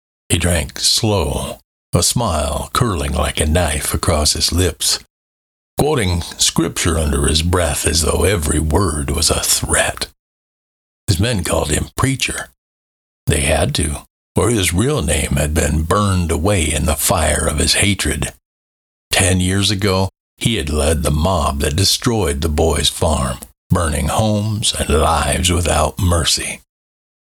3rd Person Western
Narration-Western.mp3